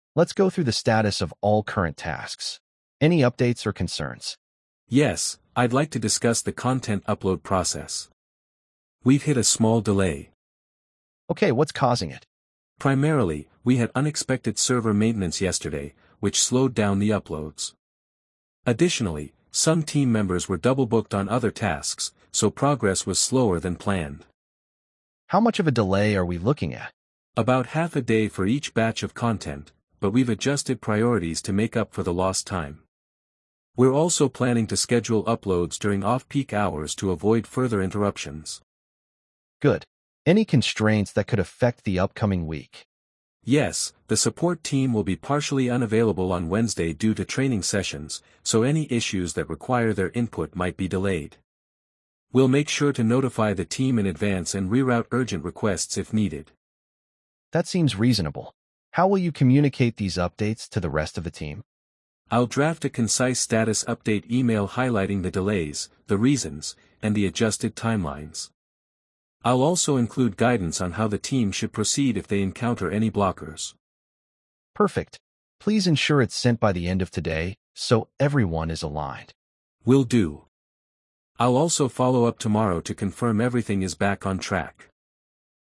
🤝 A project team reviews task status and addresses delays.